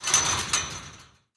ambienturban_3.ogg